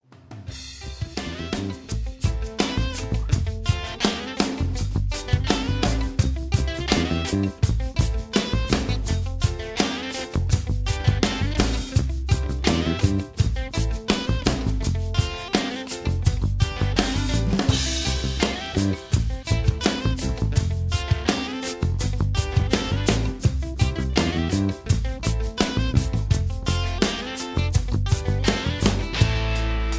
Tags: backing tracks, karaoke, sound tracks, rock and roll